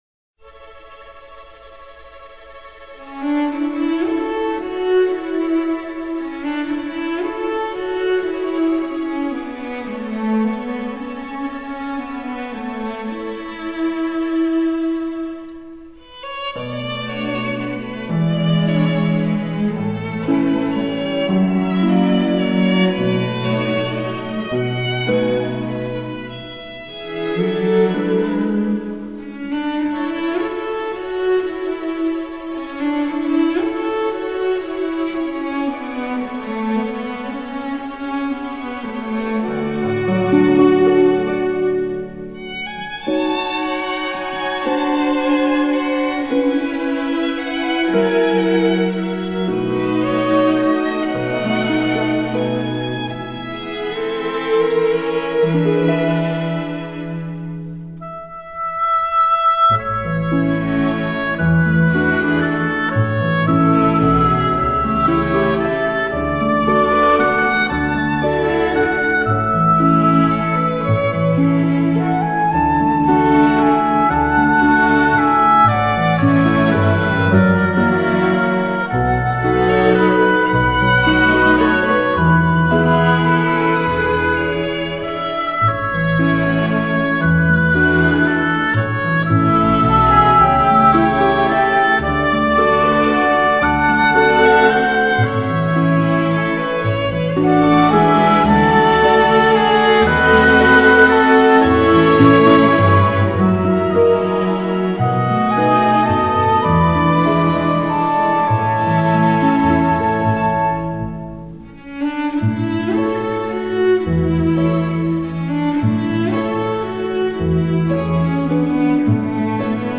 一首爱尔兰小夜曲 非常有名 听过的人应该会多一点